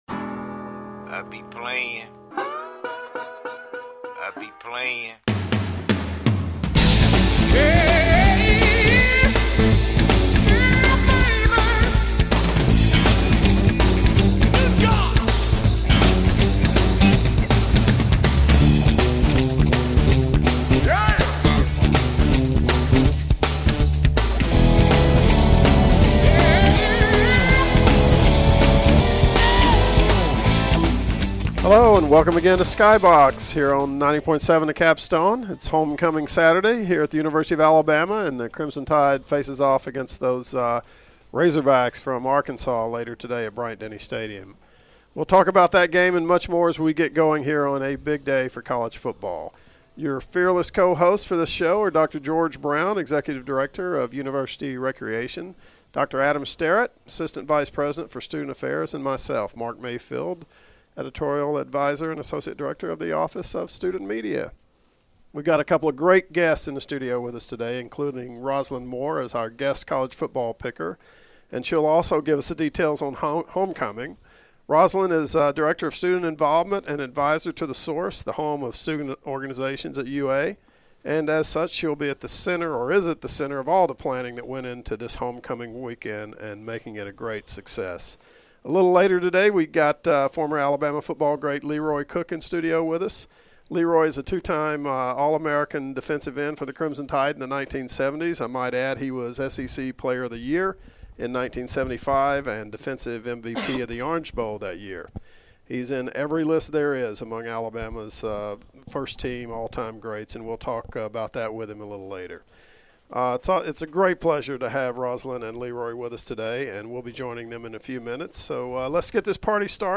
Skybox is WVUA's sports entertainment show hosted by UA professionals
an interview